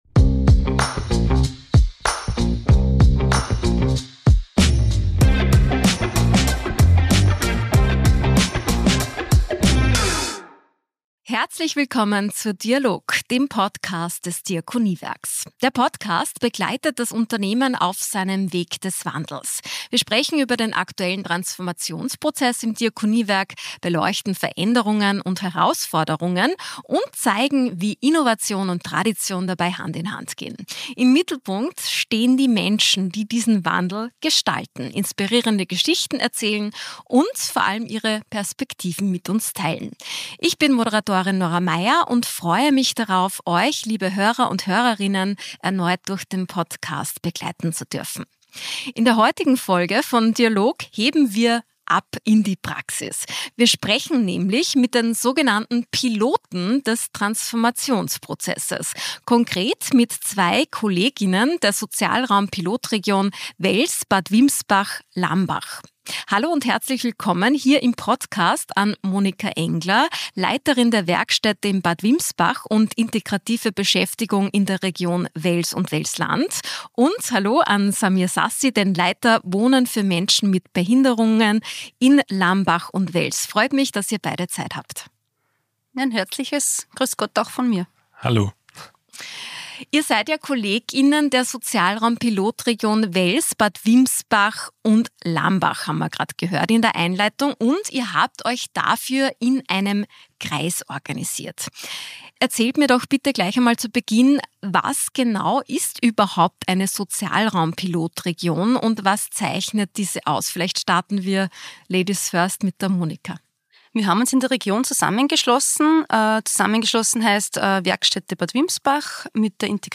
In Folge 17 von DIA-LOG sind zwei Kolleg:innen aus der Sozialraum-Pilotregion Wels/Bad Wimsbach/Lambach zu Gast vor dem Podcast-Mikrofon.